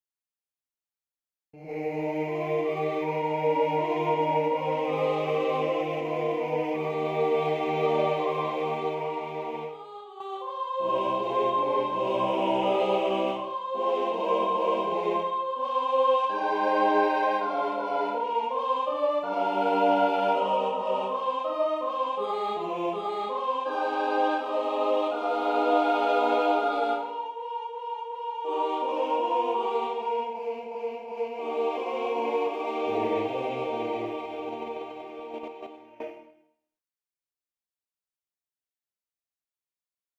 Для Смешанного хора, a cappella